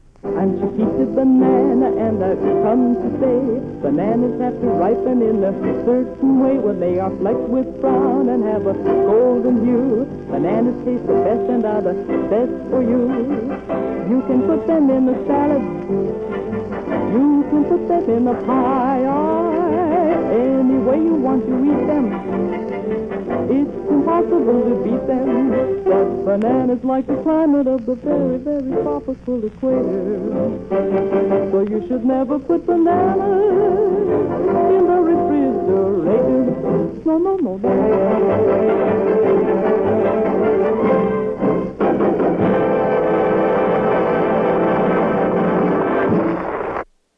Jingles: